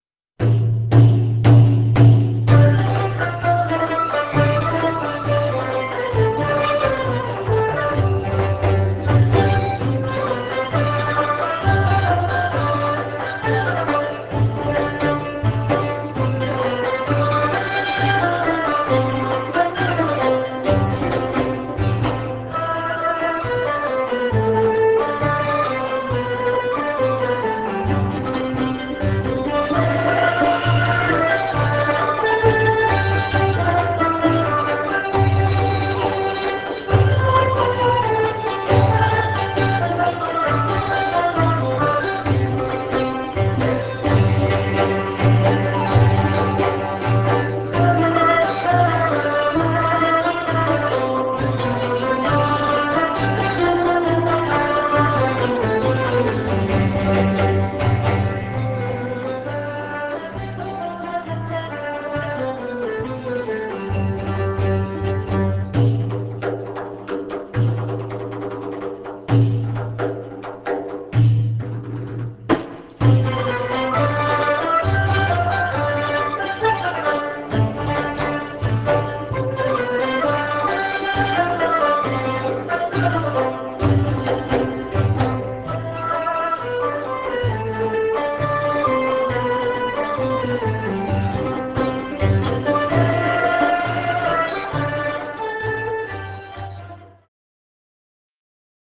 deff, zarb, tar, dumbek